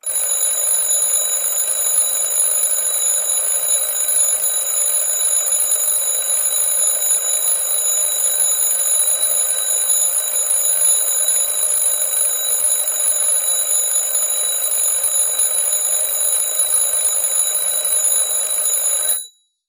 Звуки будильника